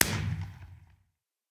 Minecraft Version Minecraft Version 1.21.4 Latest Release | Latest Snapshot 1.21.4 / assets / minecraft / sounds / fireworks / blast_far1.ogg Compare With Compare With Latest Release | Latest Snapshot
blast_far1.ogg